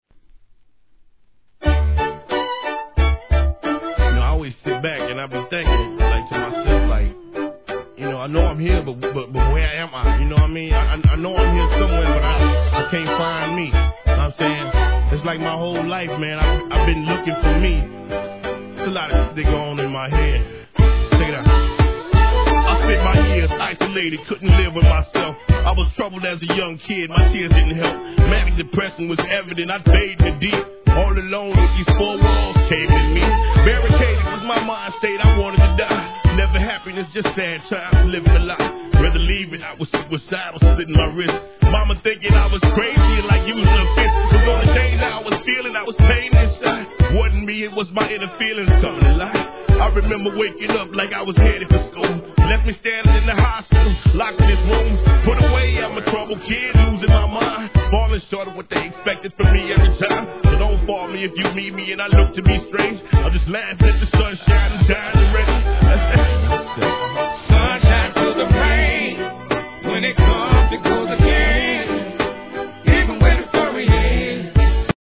HIP HOP/R&B
メロディアスなヴァイオリンのフレーズが気分を盛り上げ